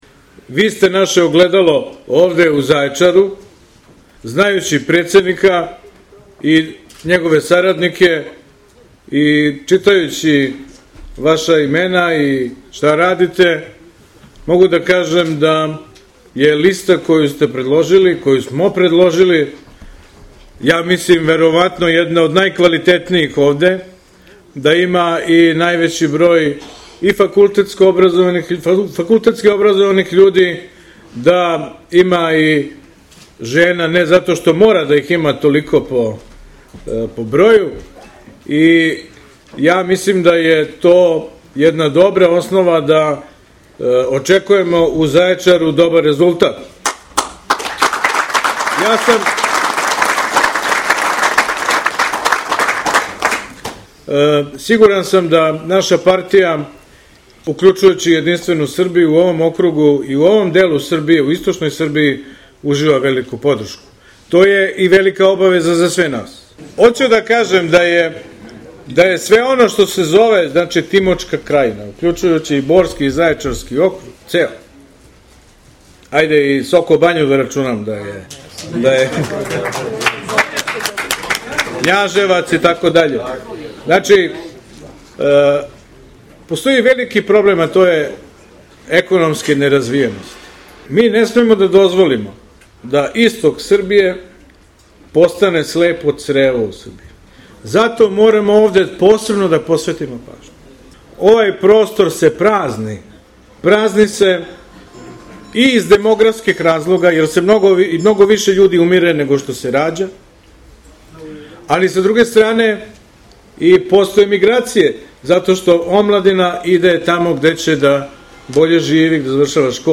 „Vi ste naše ogledalo ovde u Zaječaru. Znajući predsednika i njegove saradnike i čitajući imena i to šta radite, mogu da kažem da je lista koju smo predložili jedna od najkvalitenijih ovde. Ja mislim da je to jedna dobra osnova da očekjemo u Zaječaru dobar rezultat„, rekao je Dačić na konvenciji i dodao:
Ivica-Dacic-konvencija-Zajecar-2017-1.mp3